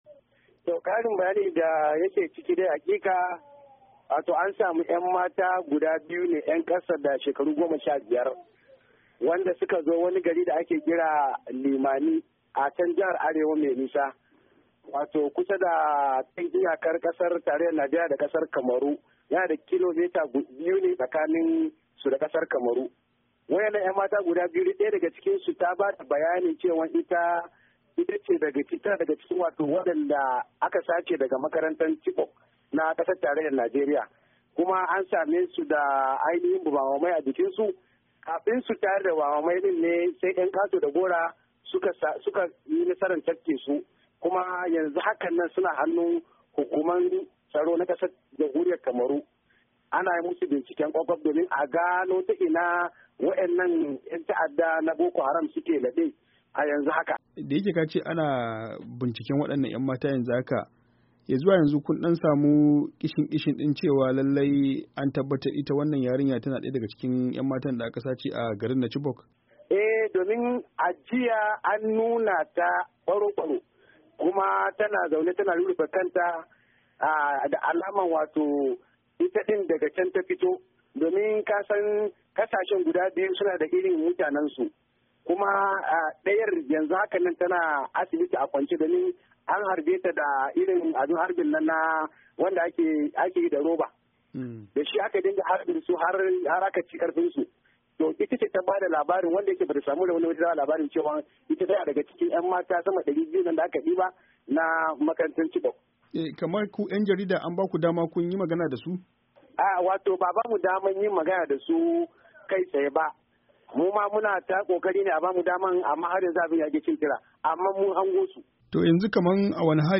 Ga kuma bayanin da yayi masa.